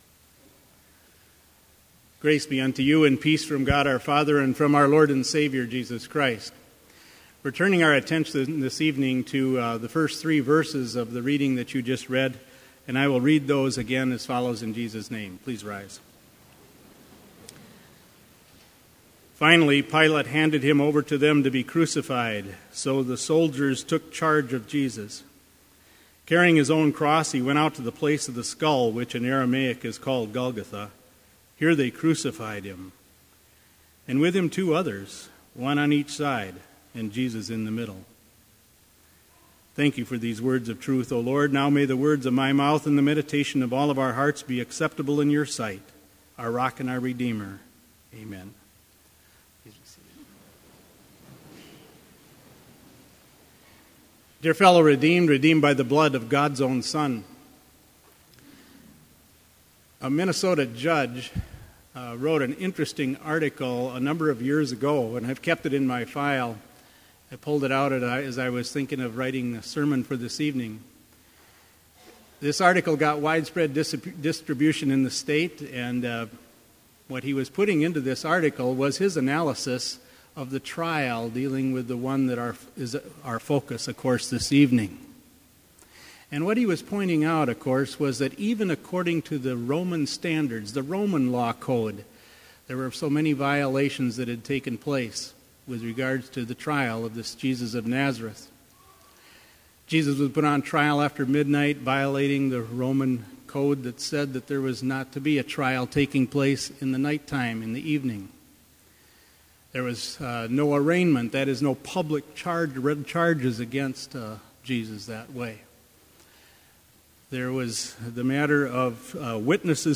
Sermon audio for Lenten Vespers - March 2, 2016